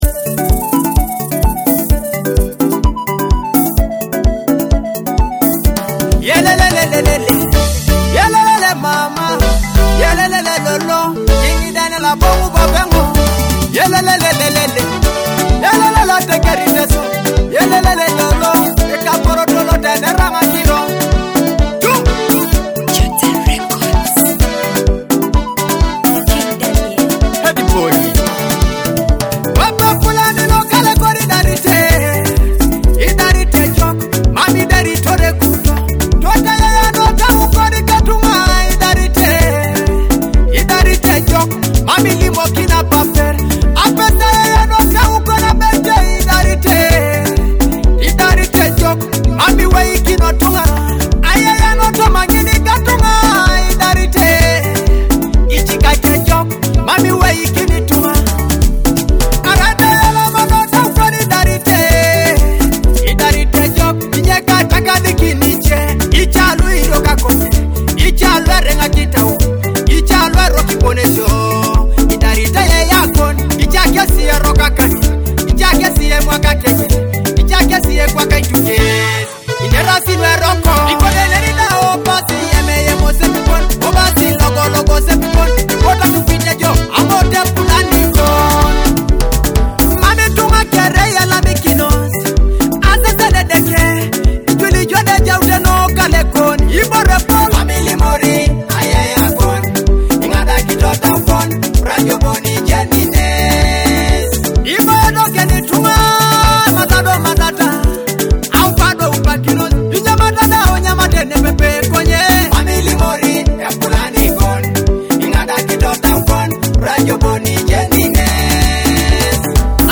a powerful Teso song about family secrets.